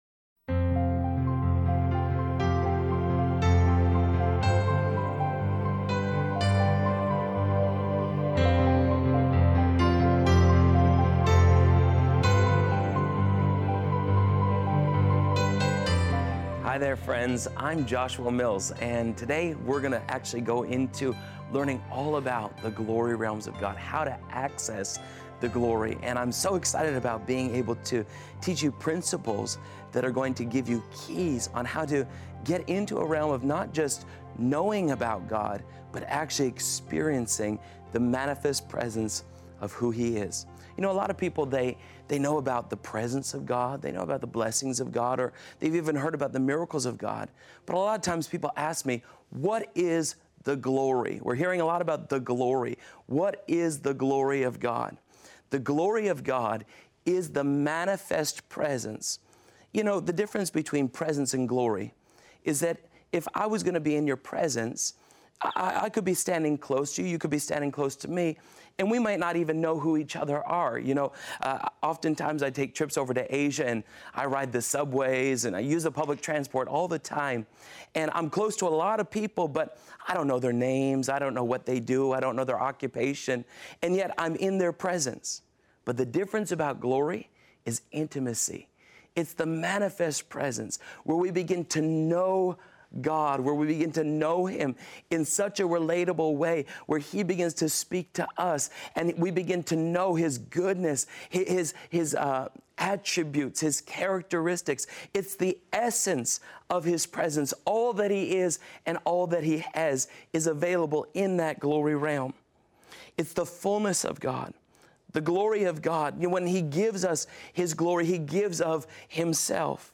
Video Lecture 1